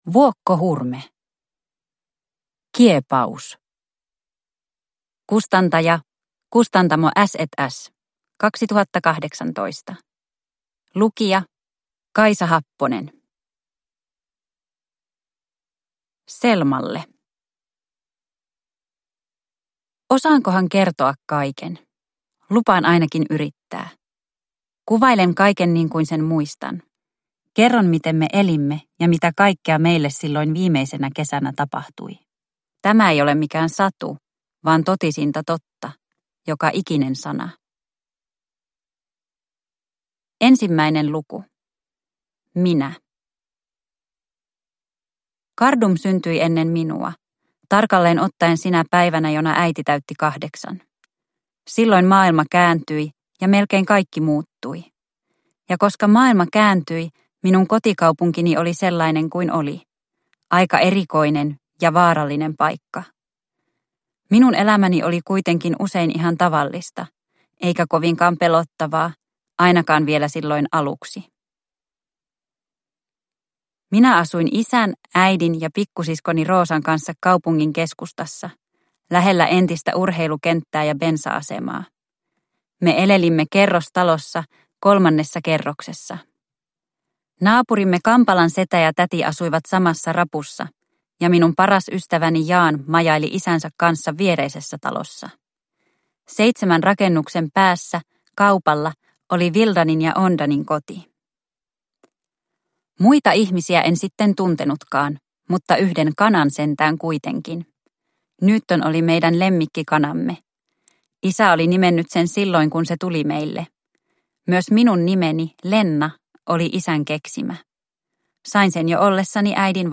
Kiepaus – Ljudbok – Laddas ner